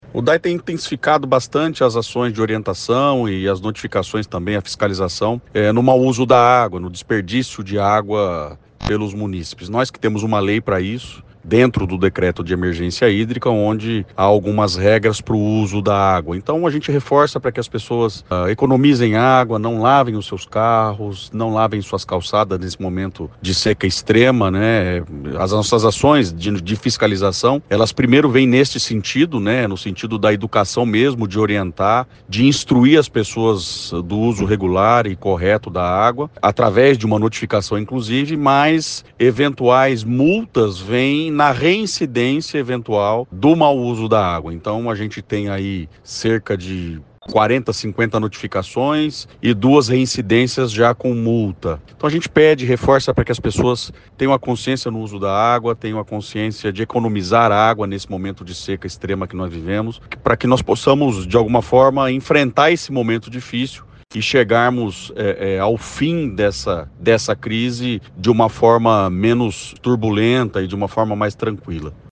Segundo o presidente do DAE, Renato Purini, esse é um trabalho de conscientização junto à população: